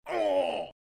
Play, download and share ROTMG Paladin death original sound button!!!!
paladin_death.mp3